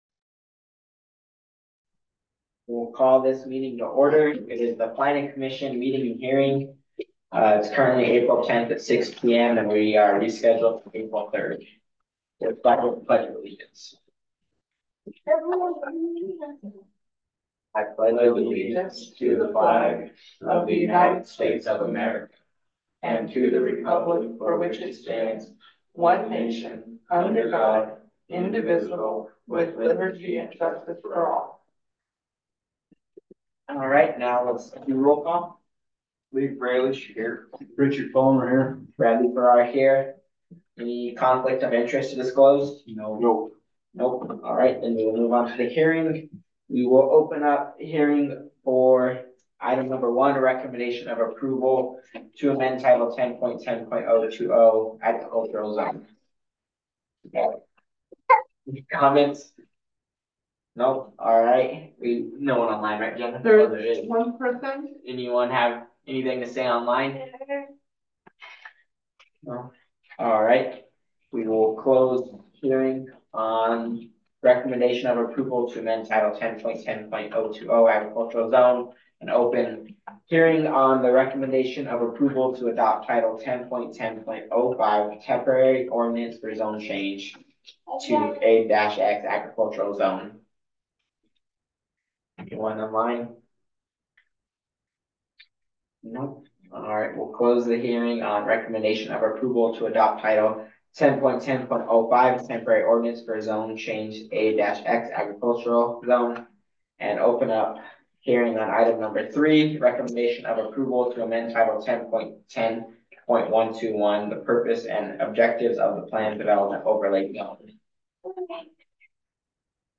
Download 4.10.24 Planning Commission Meeting and Hearing.m4a (opens in new window)